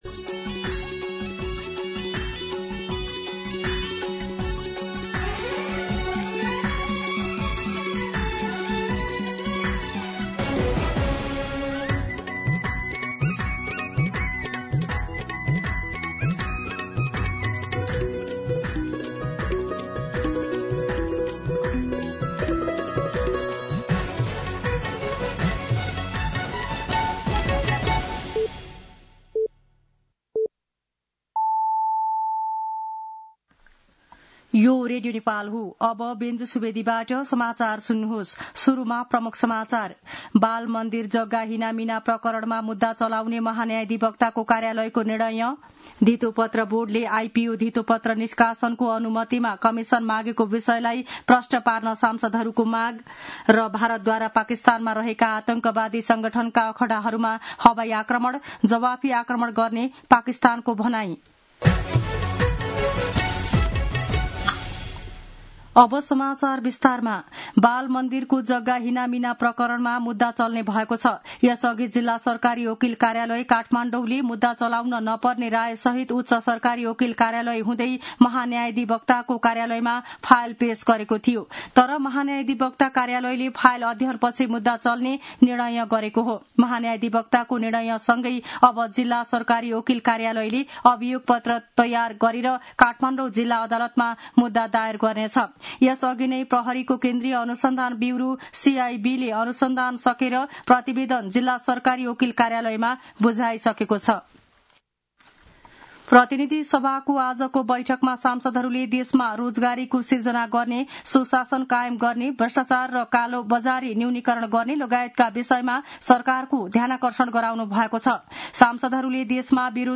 दिउँसो ३ बजेको नेपाली समाचार : २४ वैशाख , २०८२
3pm-News-01-24.mp3